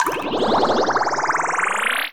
water_bubble_spell_heal_03.wav